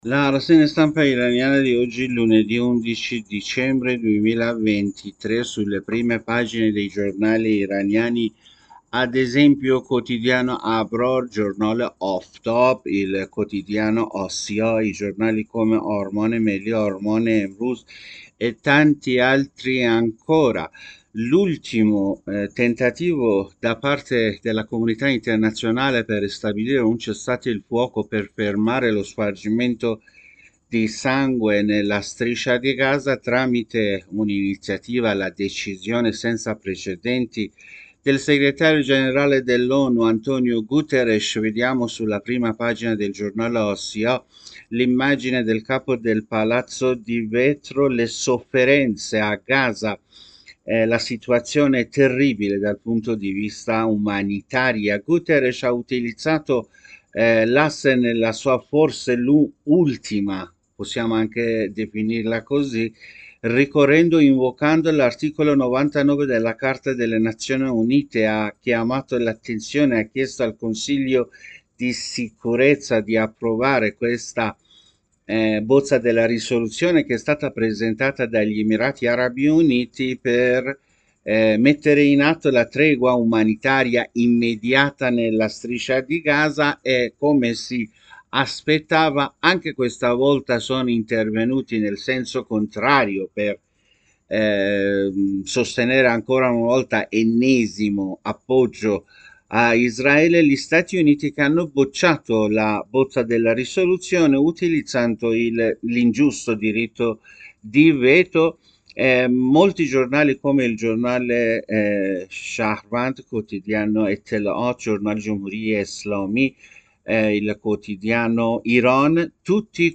Rassegna Stampa Iran Lunedì 11 Dicembre 2023 (AUDIO)